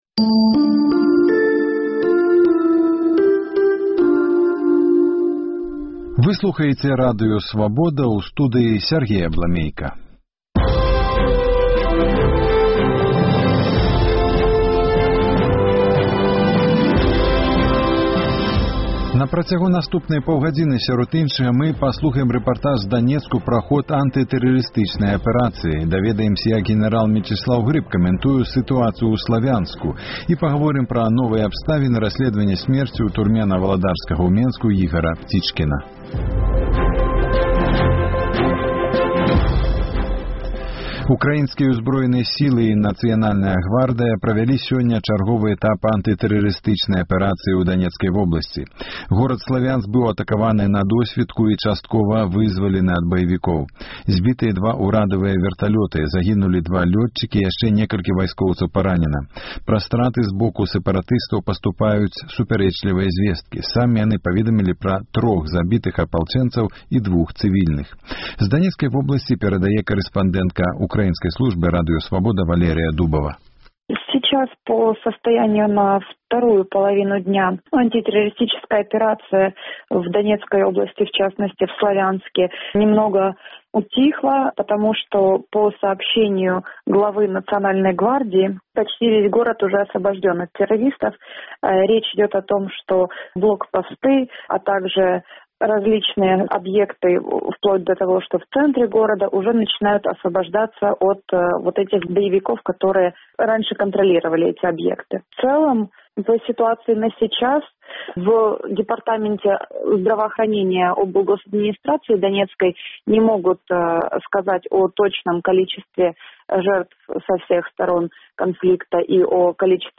У праграме рэпартаж з Данецку
улады шукаюць наркатычны сьлед Апытаньне ў Гомелі